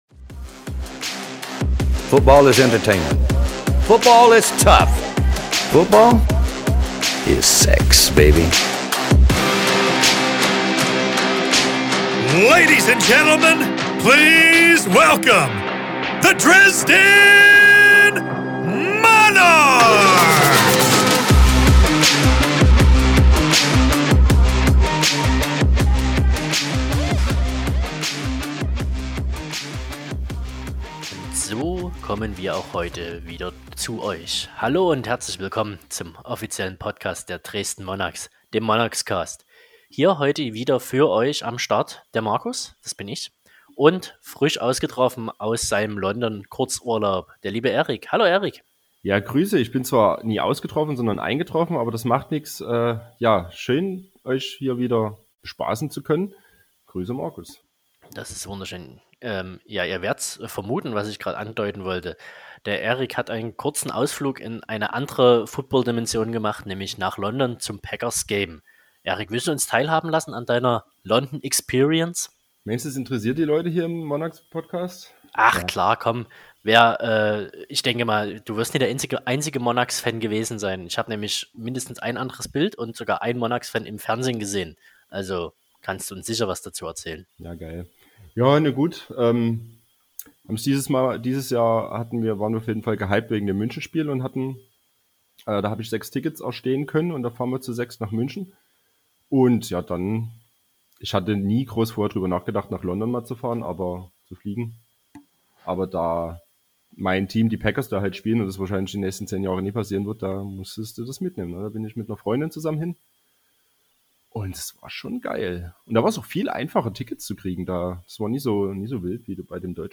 Außerdem wird immer ein interessanter Gast in einem Interview Rede und Antwort stehen.